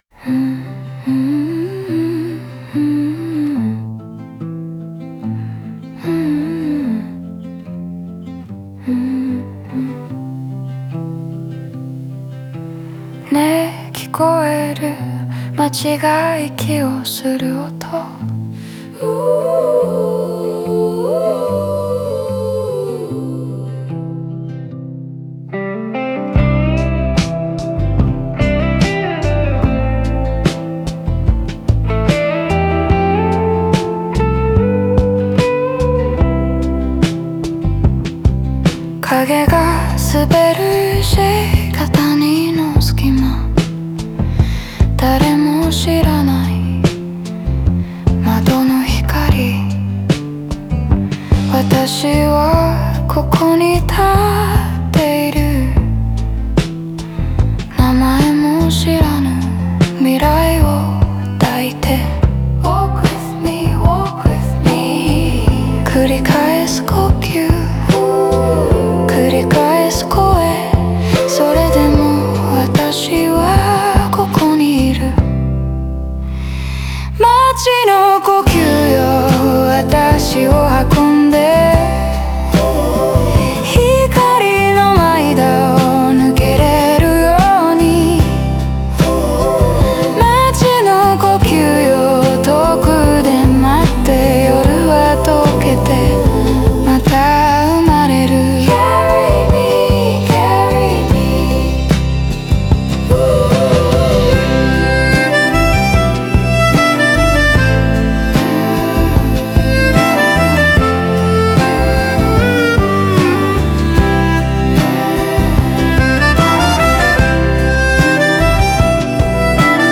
バックコーラスは英語で重なり、内面の声が外界と共鳴するような幻想的な雰囲気を添えている。